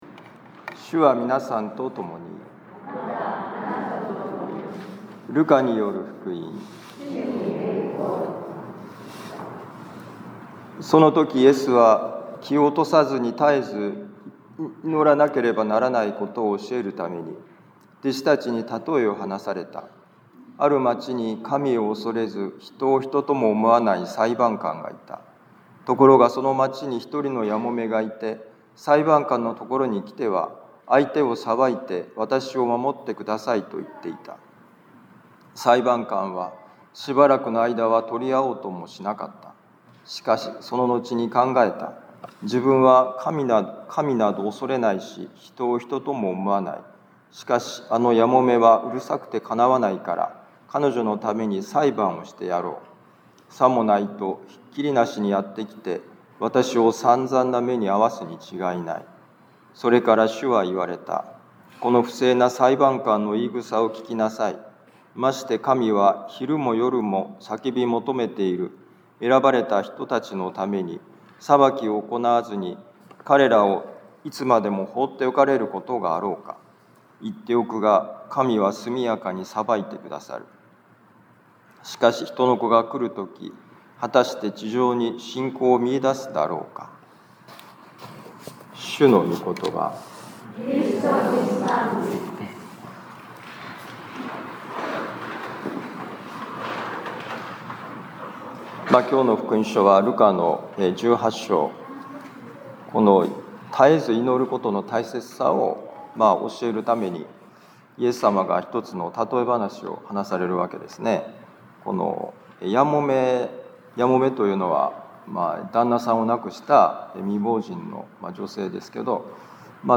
ルカ福音書18章1-8節「願い求めるプロセスこそ」2025年10月19日年間第29主日ミサ防府カトリック教会